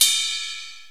Crashes & Cymbals
Amsterdam Ride.WAV